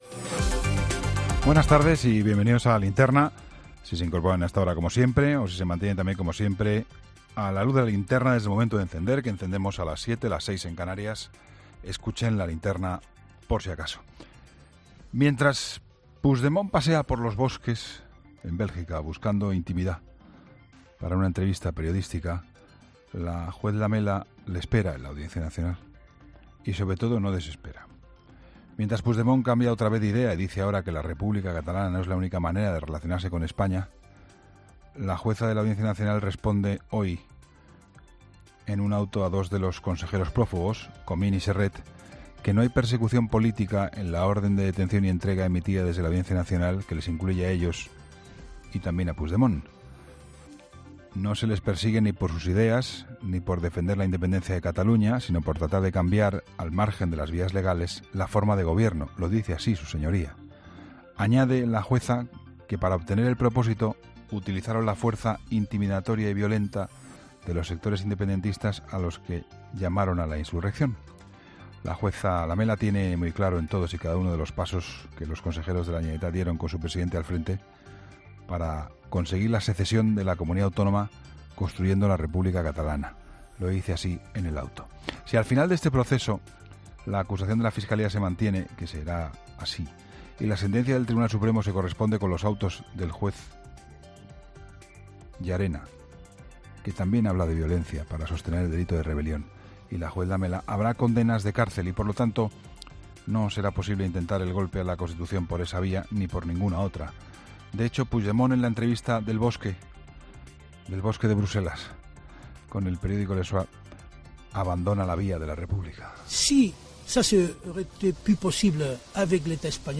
El análisis de Juan Pablo Colmenarejo de este lunes sobre lo que está aconteciendo con Carles Puigdemont y Cataluña